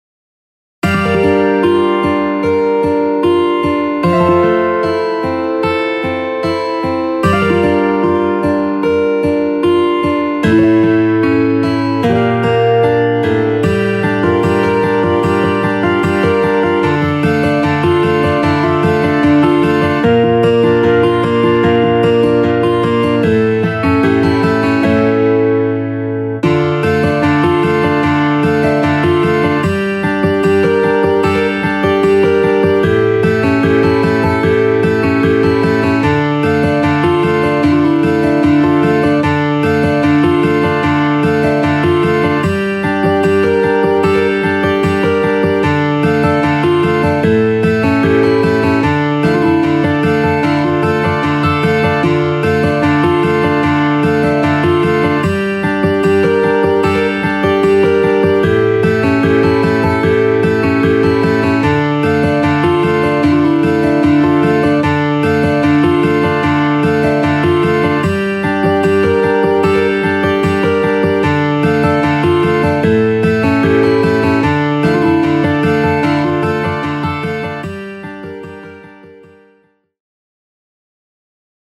HALion6 : A.Guitar
12 String Dteel